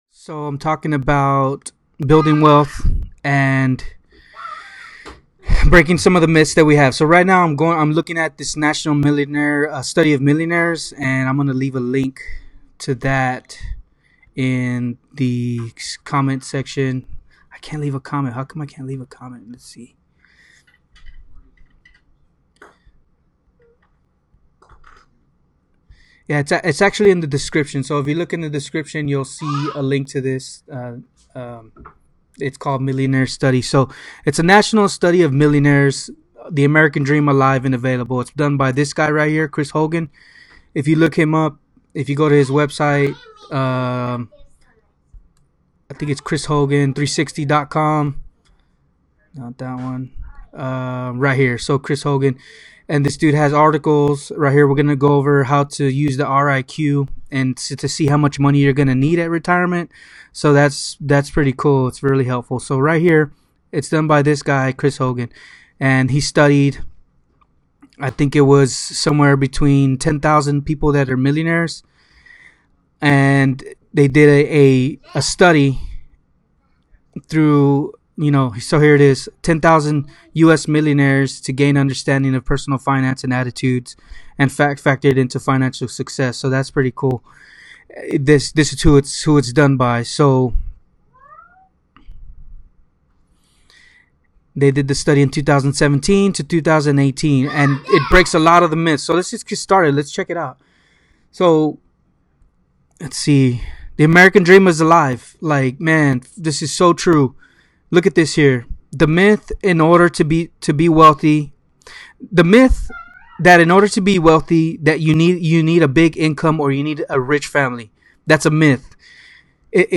The video for this podcast was recorded live on my facebook page.